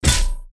metal_punch_06.wav